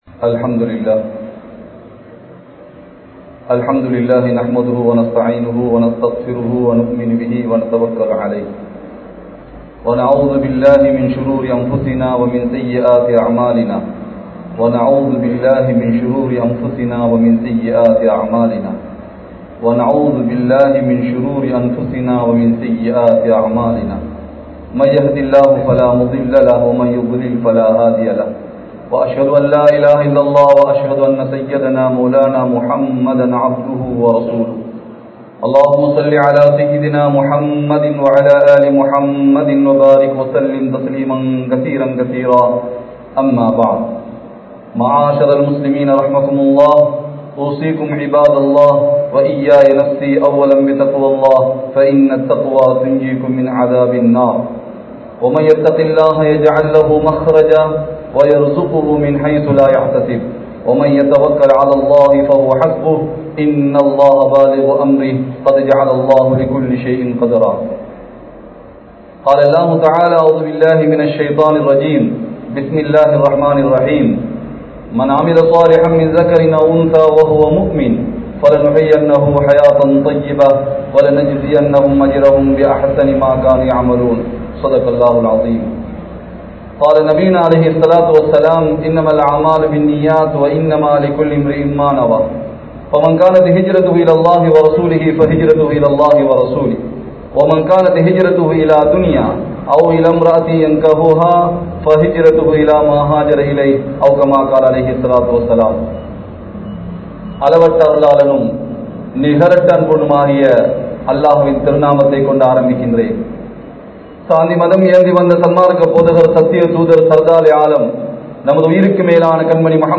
Allahvin Meethu Ungalukku Payam Illaya? (அல்லாஹ்வின் மீது உங்களுக்கு பயம் இல்லையா?) | Audio Bayans | All Ceylon Muslim Youth Community | Addalaichenai